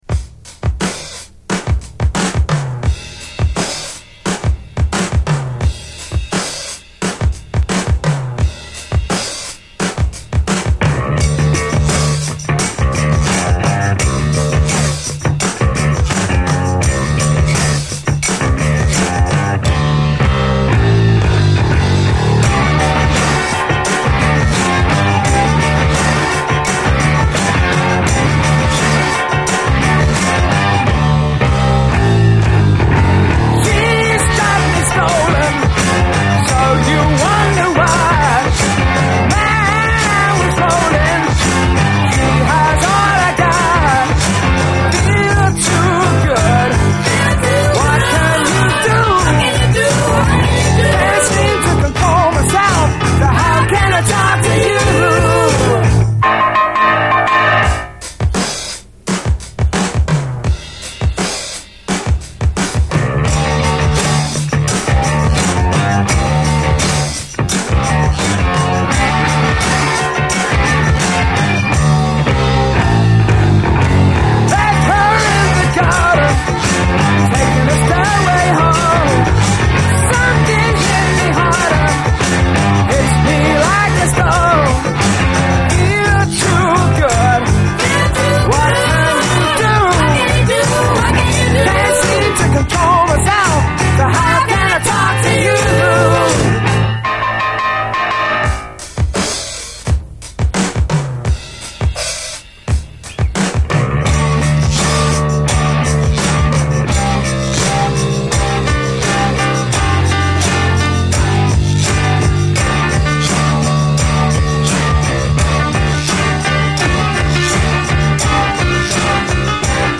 70's ROCK, ROCK
ドラム・ブレイク入りの変幻自在サイケ・ロック
ナイス・ドラム・ブレイクが何度も出て来る熱きサイケ・ロック
出音もよいです。